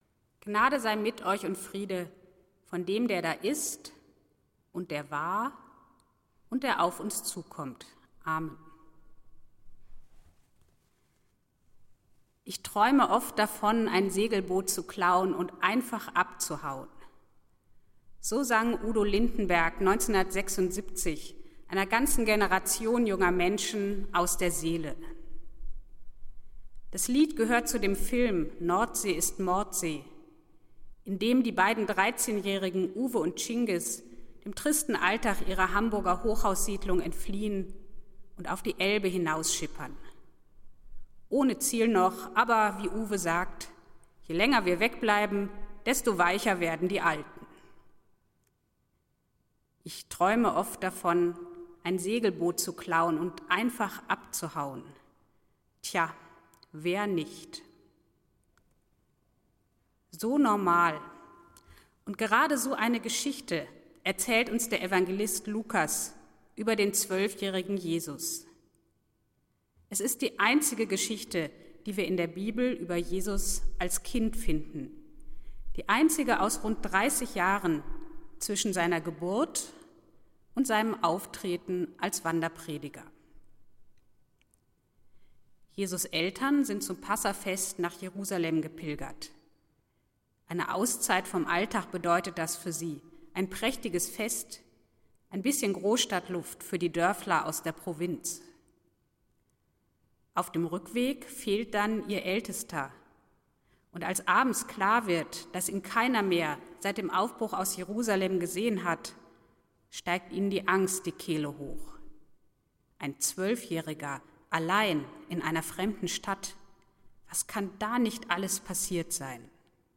Predigt des Gottesdienstes aus der Zionskirche vom Sonntag, 03.01.2021